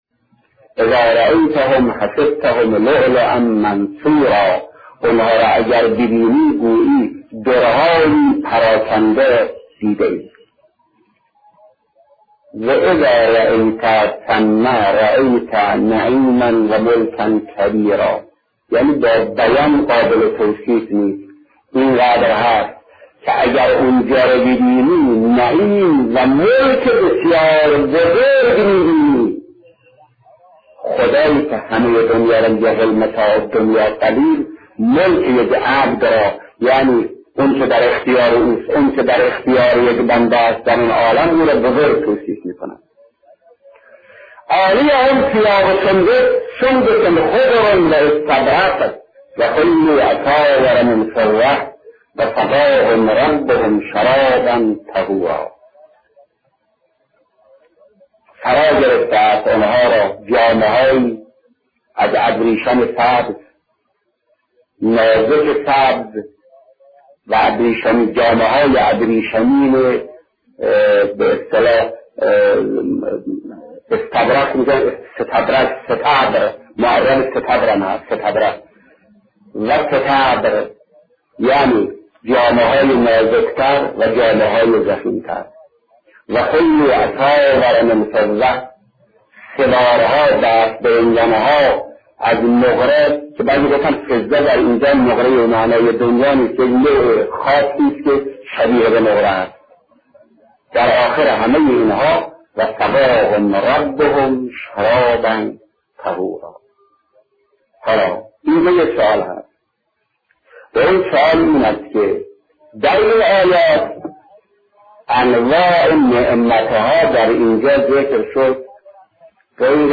شهید مطهری درباره نعمت های بهشتی صحبت می‌کند. تفسیر آیات سوره انسان.